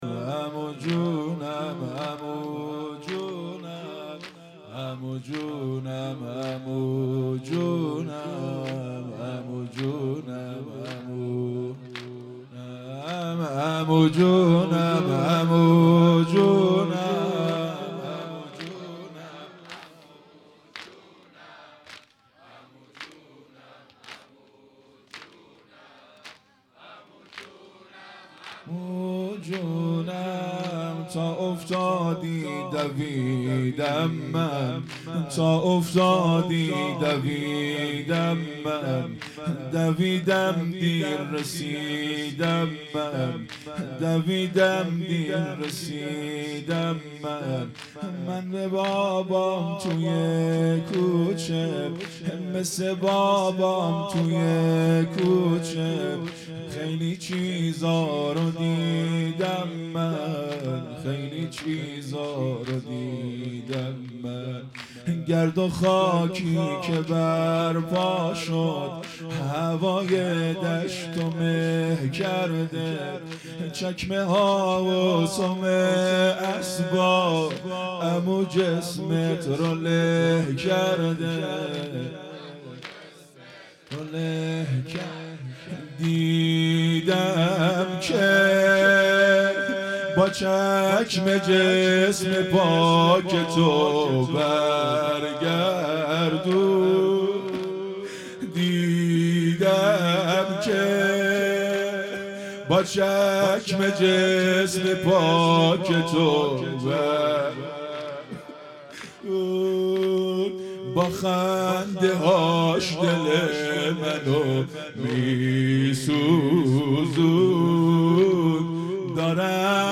زمینه|عمو جانم عمو جانم
هیئت مکتب الزهرا(س)دارالعباده یزد
محرم ۱۴۴۵_شب پنجم